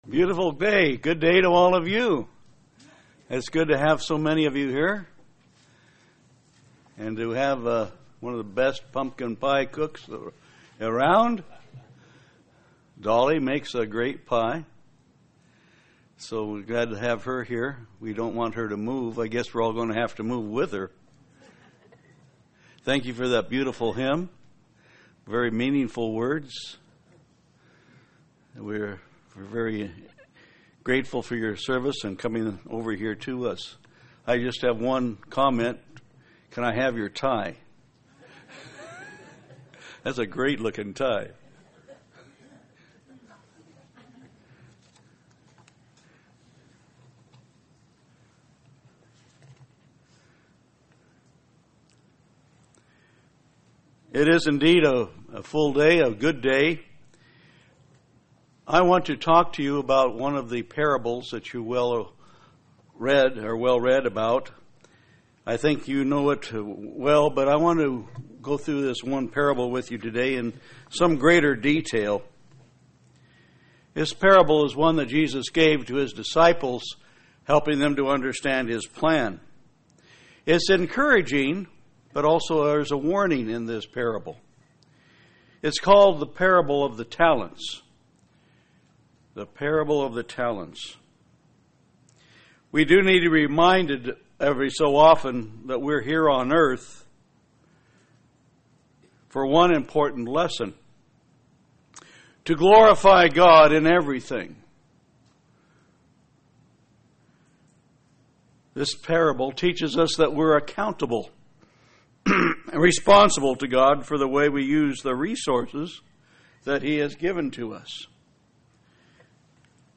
This sermon examines The Parable of the Talents in very close detail.